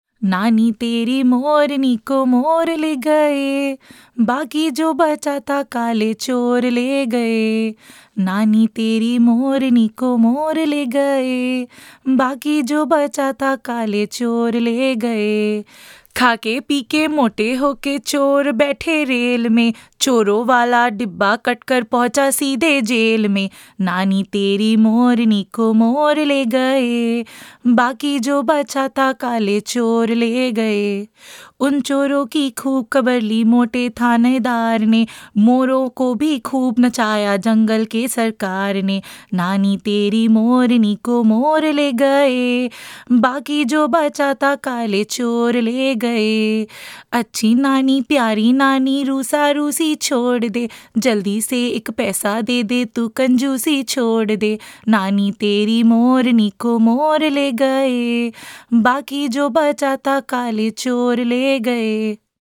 Nursery Rhymes